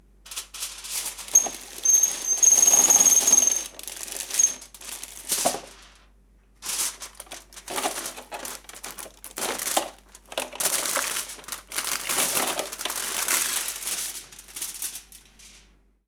Tirar de un rollo de papel de aluminio
Cocina
Sonidos: Acciones humanas
Sonidos: Hogar